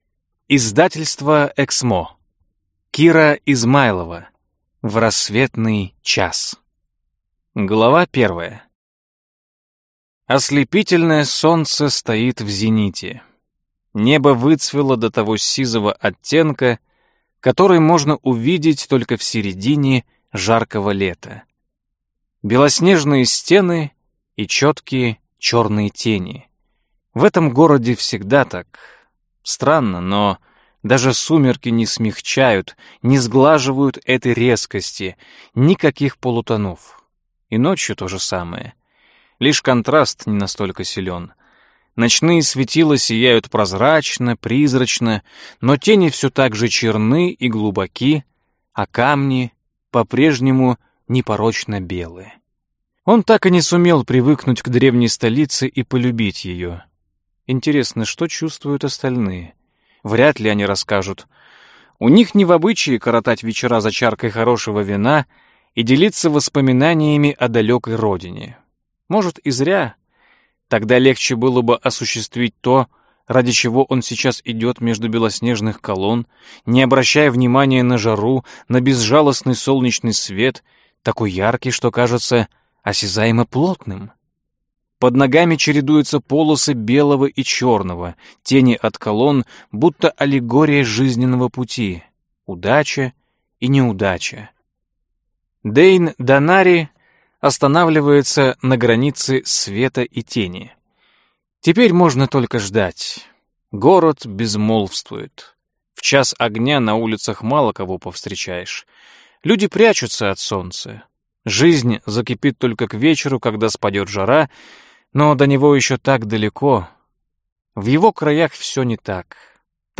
Аудиокнига В рассветный час - купить, скачать и слушать онлайн | КнигоПоиск